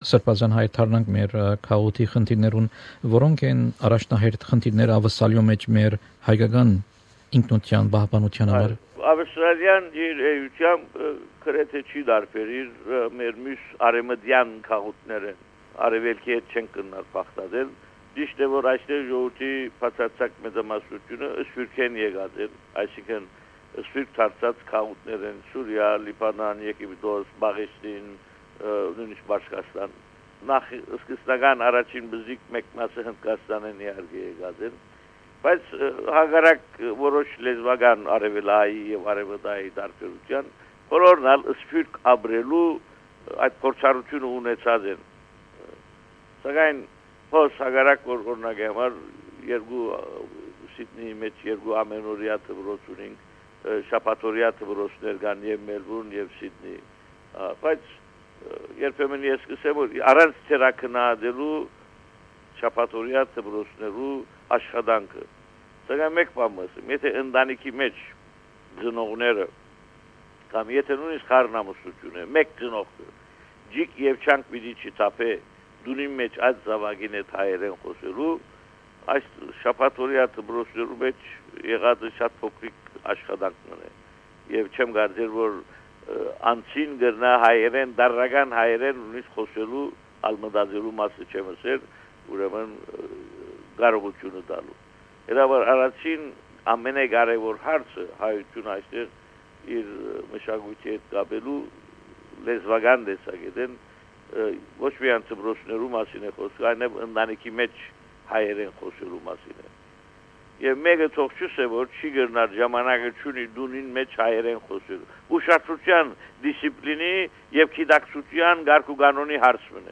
Interview with Bishop Haygazoun Najarian, Primate of Armenians in Australia & New Zealand on Armenian identity.